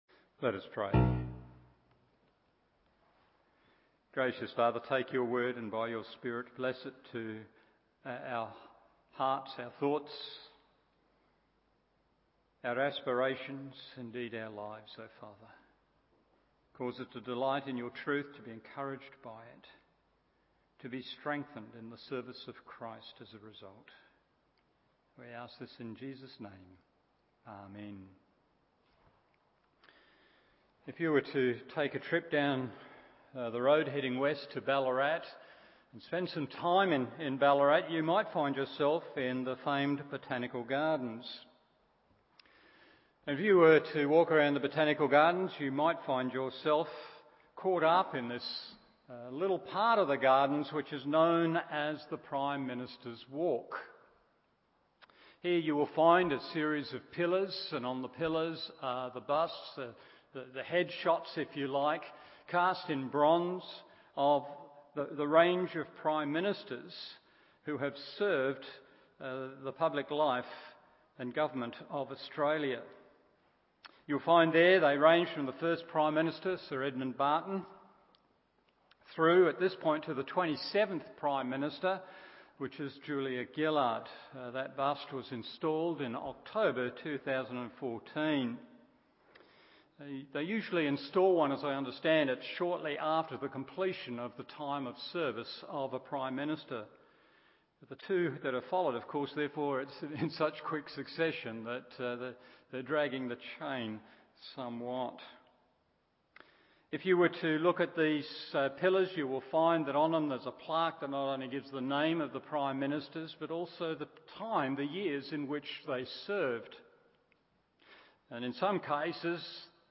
Evening Service Hebrews 11:1-12:2…